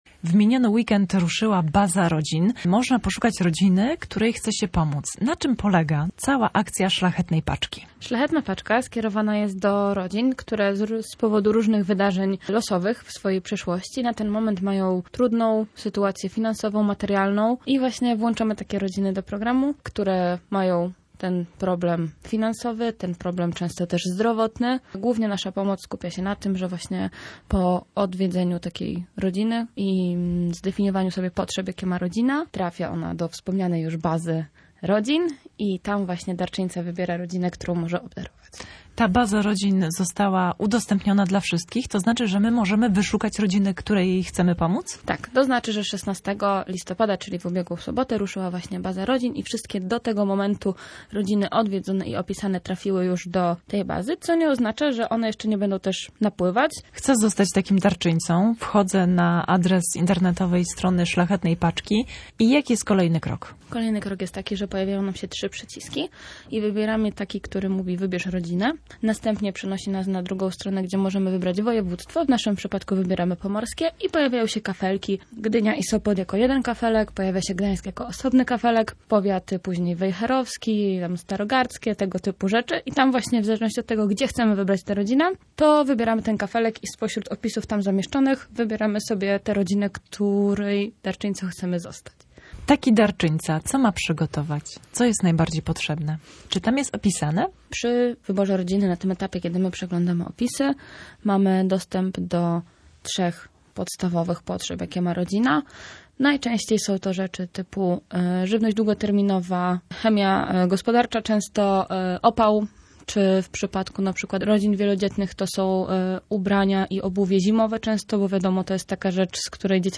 Posłuchaj całej rozmowy: /audio/dok3/szlachetnapaczka112019.mp3